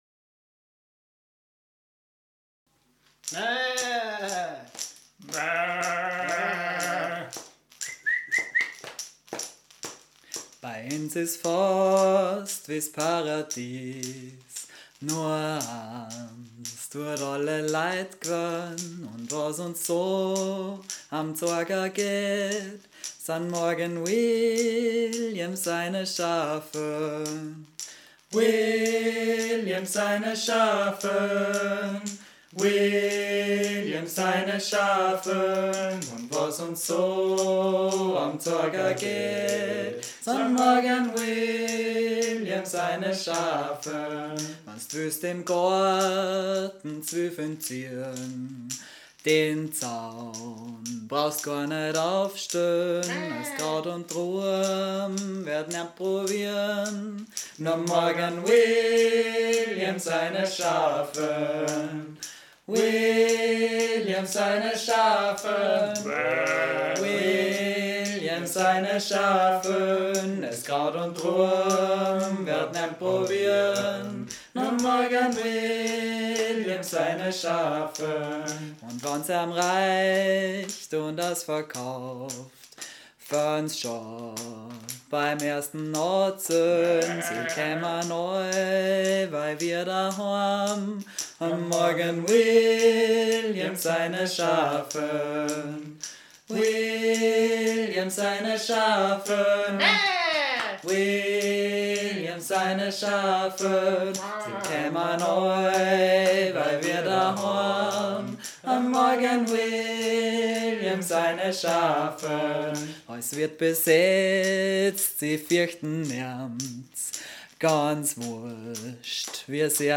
das traditionelle walisische Lied »Defaid William Morgan« in den Salzburger Dialekt übertragen